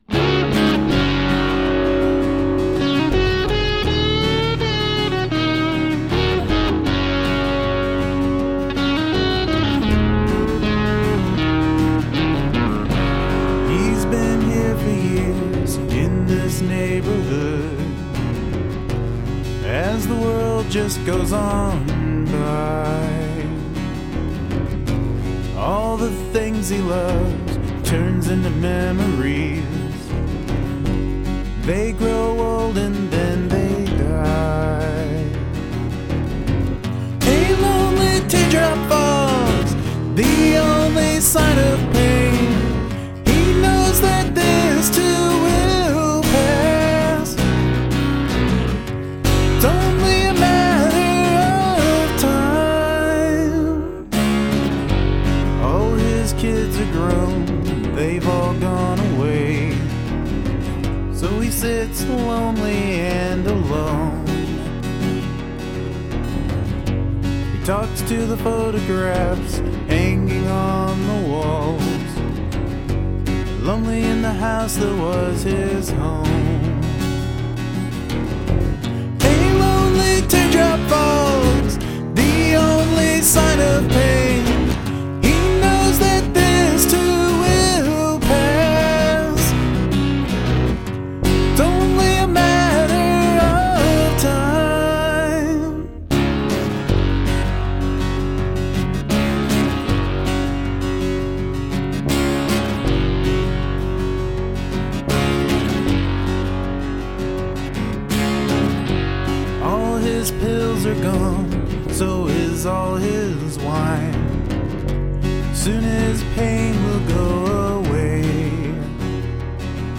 Me: Acoustic guitar, acoustic guitar with fun br twincrunch effects, bass guitar, vocals
Another great acoustic rocky tune, love your vox there always excellent, enjoyed the listen.
I think its fine without the drums.
nice simple production, too, with a nice amount of sonic crunch in the right places :)
Leave the drums alone - this has a lovely strummed rhythm that carries the song along very nicely.